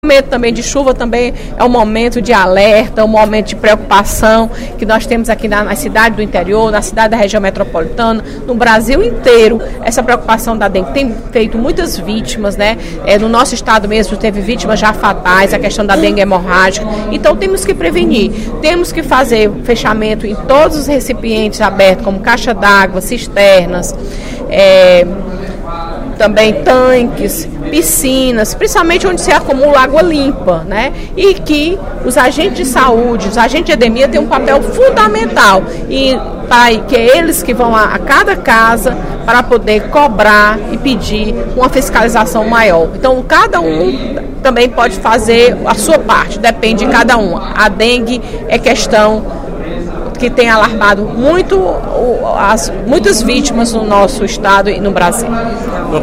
Durante o primeiro expediente da sessão plenária desta terça-feira (07/04), a deputada Fernanda Pessoa (PR) fez um alerta à população cearense a respeito do aumento no número de casos de dengue registrados no Ceará.